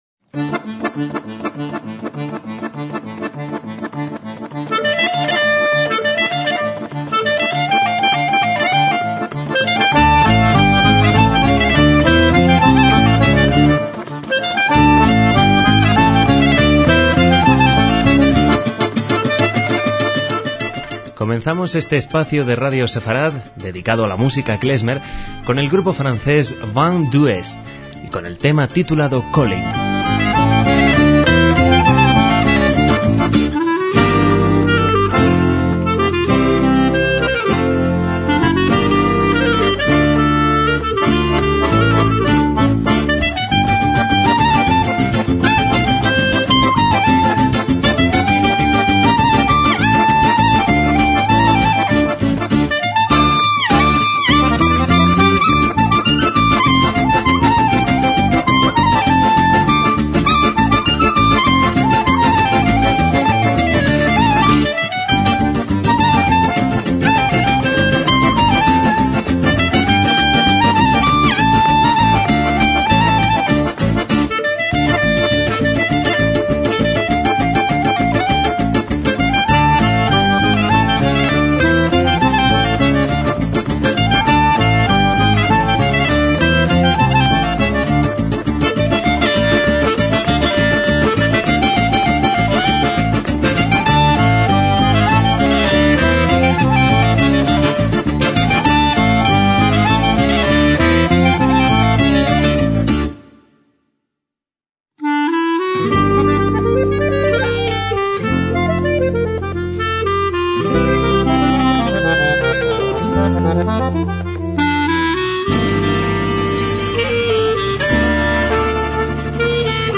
MÚSICA KLEZMER
clarinete/saxofón
acordeón
guitarra
contrabajo